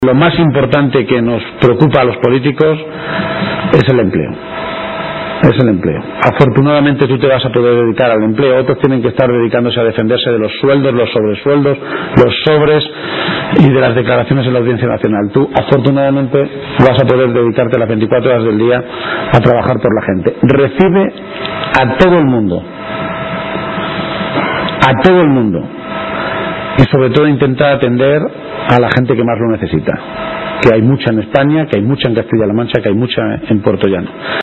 Unas palabras pronunciadas por García-Page en Puertollano, municipio al que ha acudido para participar en un acto informativo a la militancia organizado para dar detalles del proceso de cambio y renovación que se producirá en apenas dos semanas con el relevo en la Alcaldía que Joaquín Hermoso Murillo dará a Mayte Fernández.